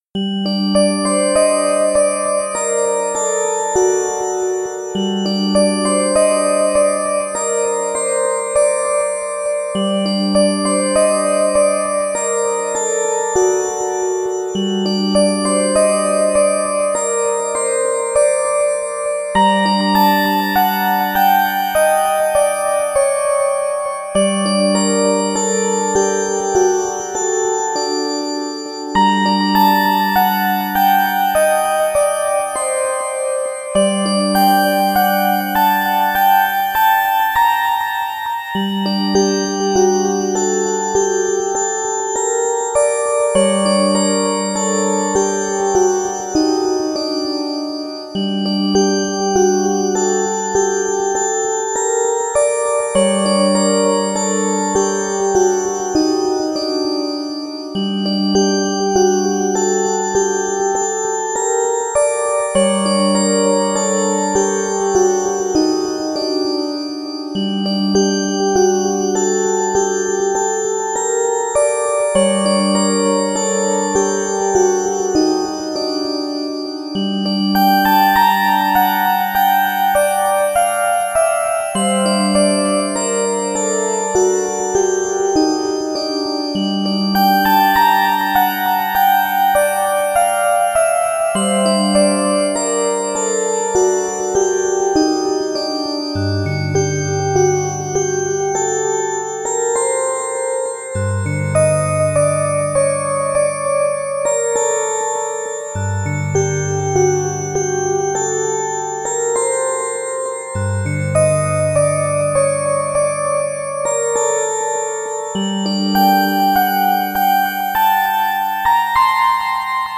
不思議な雰囲気の曲です。【BPM100】